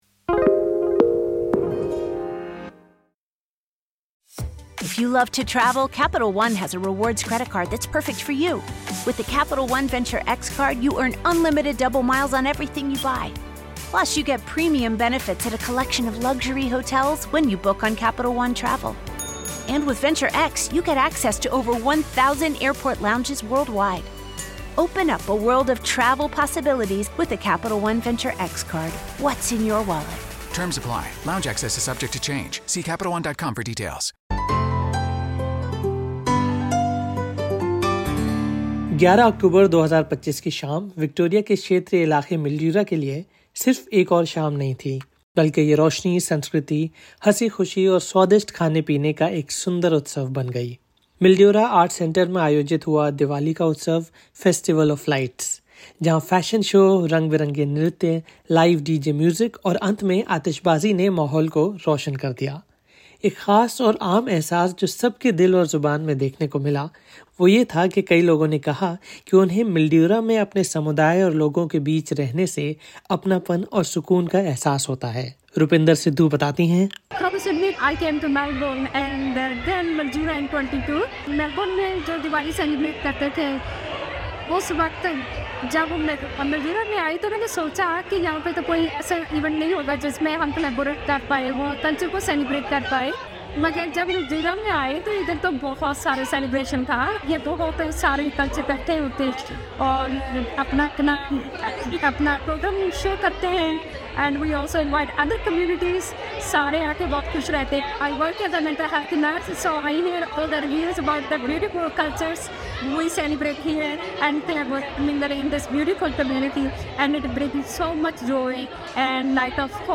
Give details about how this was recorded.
In this episode, we take you to Mildura in regional Victoria, where Diwali Utsav, the Festival of Lights, turned an ordinary evening into a vibrant celebration of culture, music and togetherness.